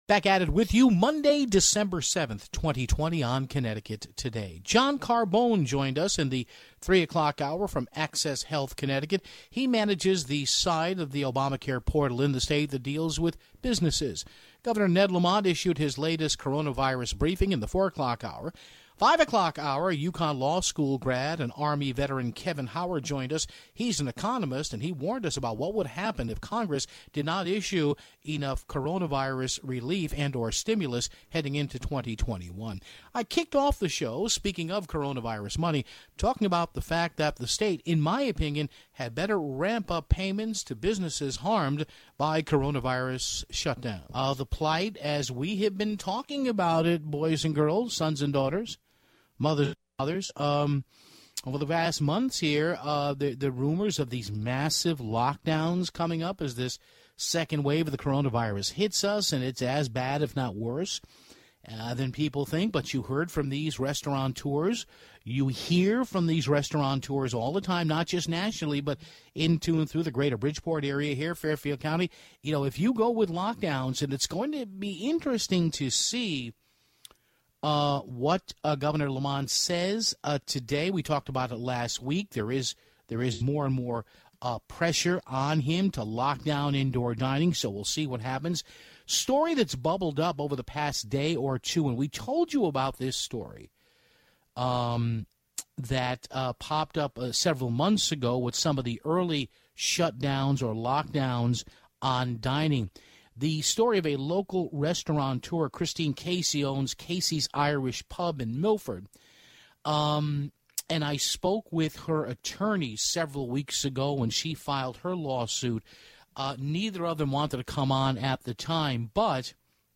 Following Governor Ned Lamont's address to the media (24:55)